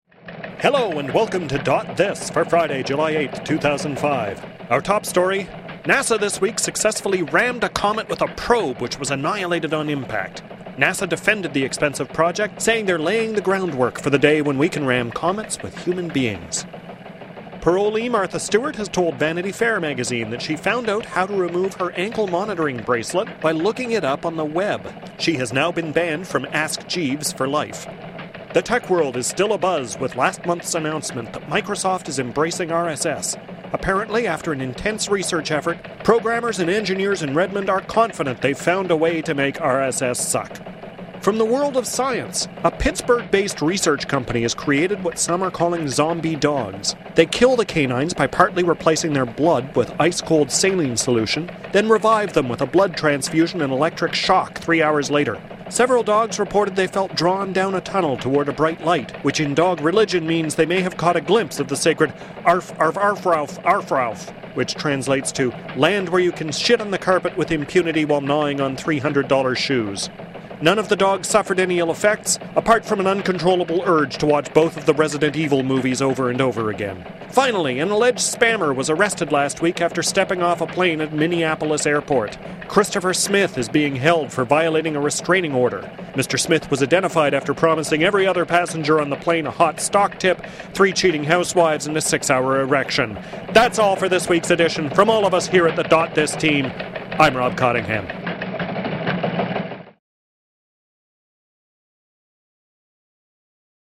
Because dotThis! is a tech news roundup like no other.